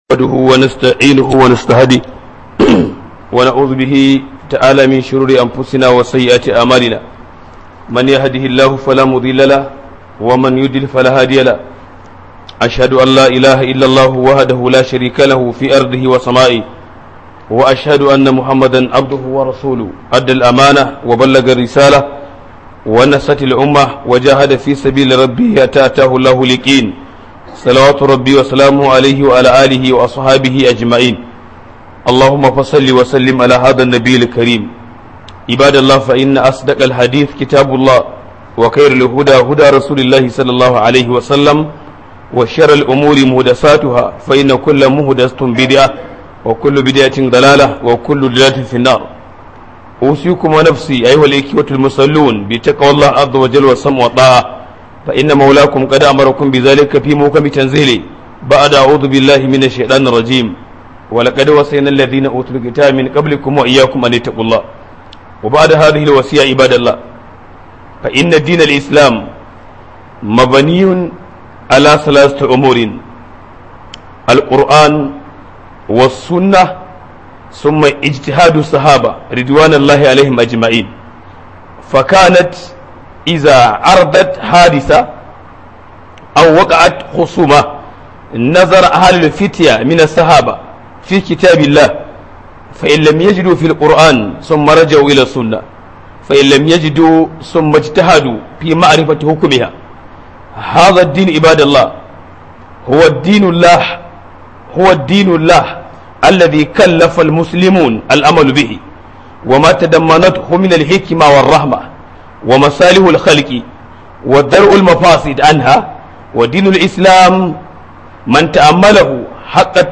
072 Huduba Mai Taken Waywaye Adon Tafiya